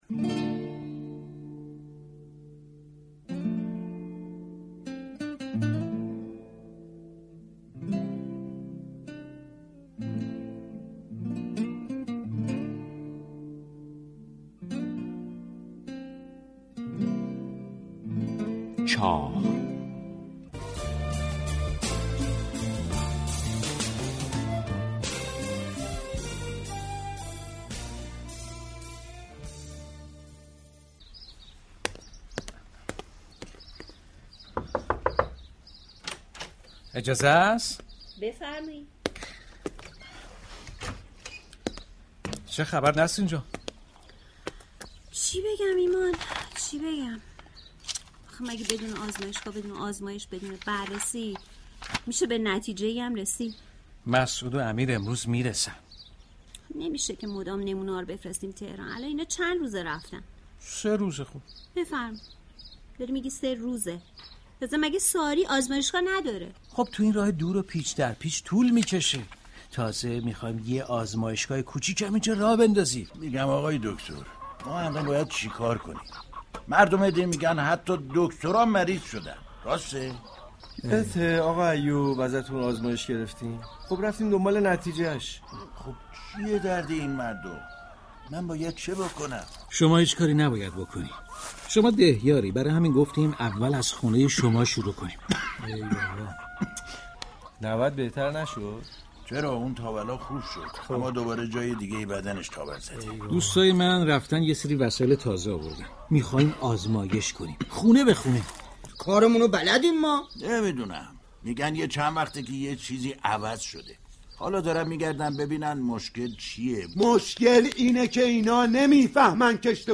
داستان خانه بهداشت یك روستا در نمایش جدید رادیویی